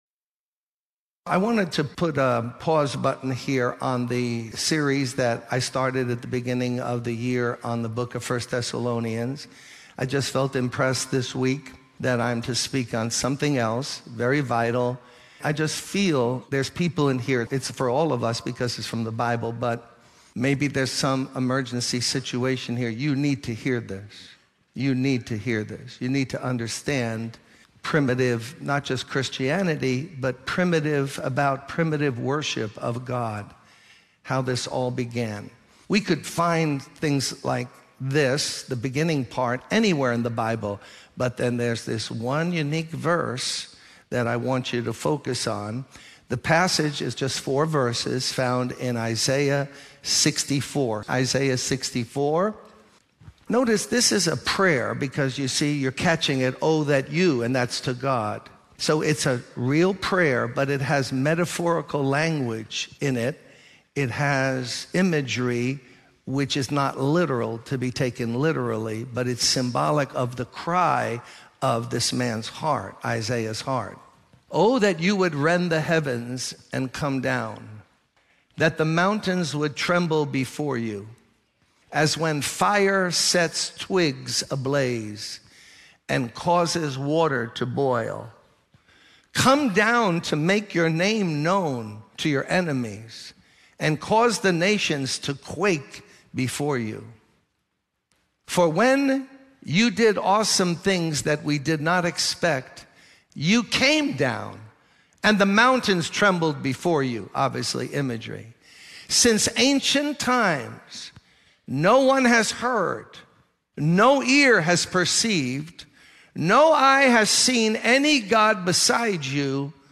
In this sermon, the speaker shares personal experiences and lessons learned about the power of waiting on God. He emphasizes the importance of relying on God's power rather than our own cleverness.